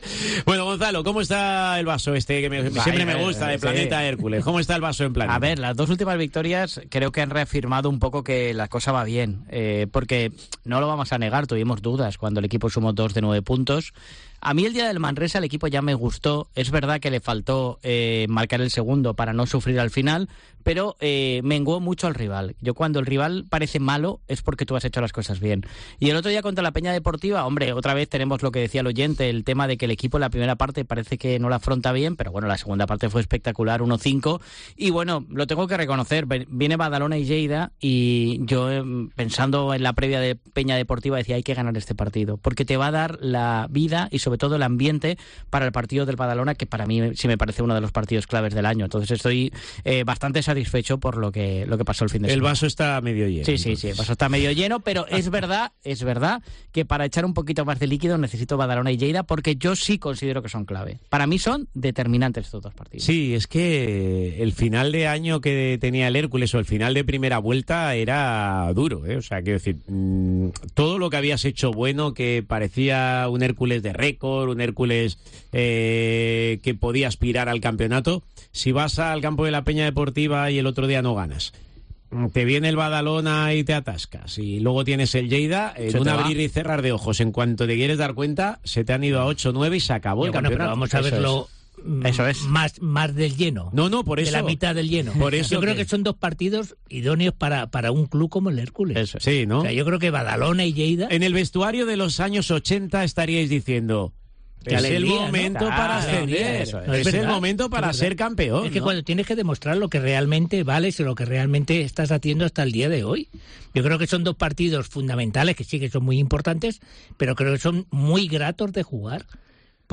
No te pierdas la Tertulia XXL con el pulso Hércules y Lleida por el primer puesto como tema central del día